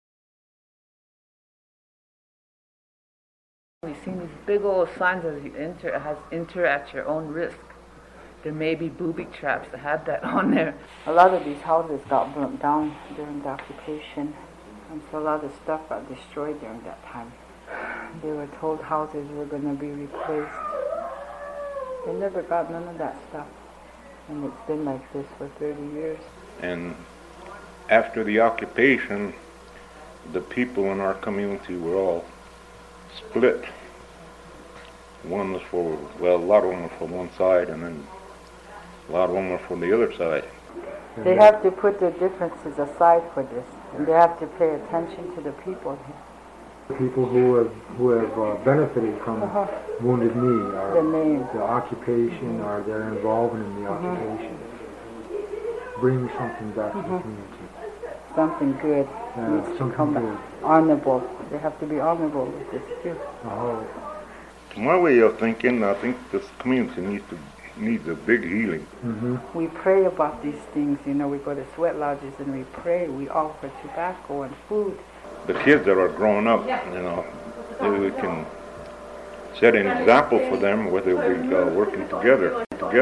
These three radio reports provide new insights on the occupation
Residents recall